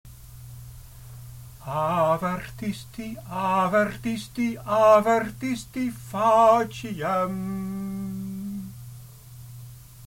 Het is logisch om de herhalingen te laten culmineren, door bijv. gebruik te maken van een stijgende sequens: